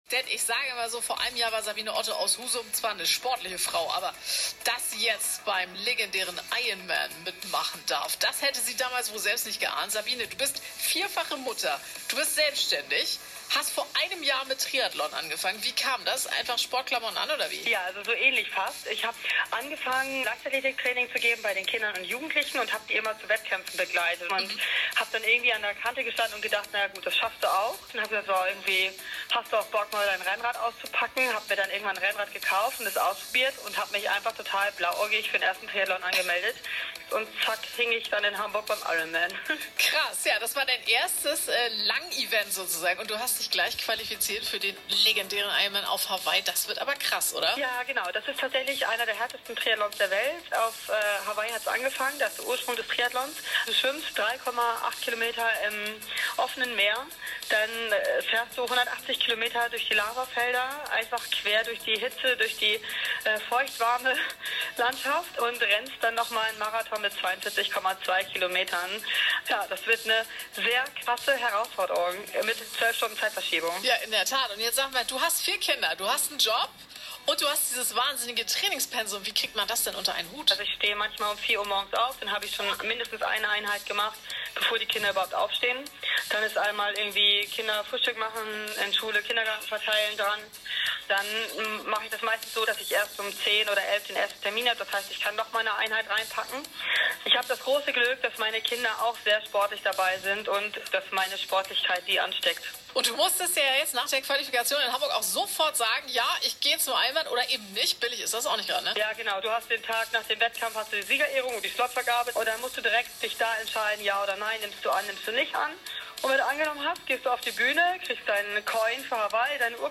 Live im Interview bei R.SH